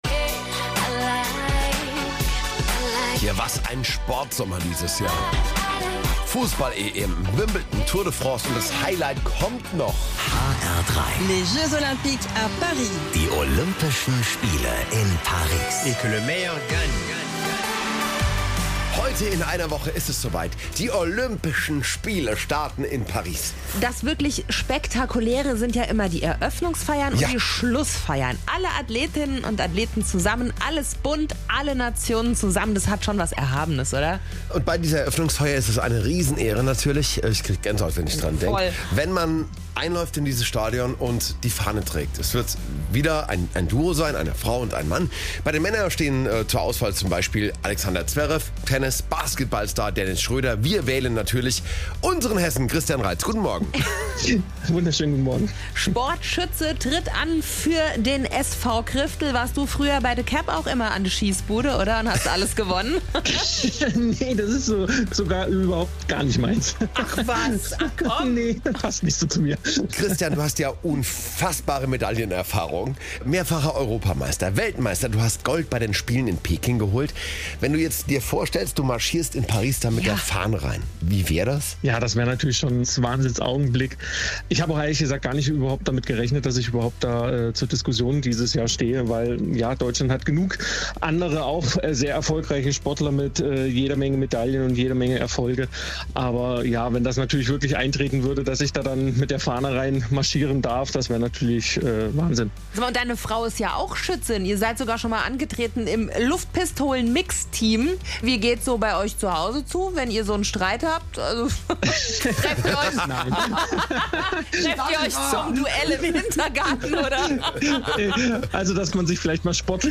Christian Reitz im Talk mit HR3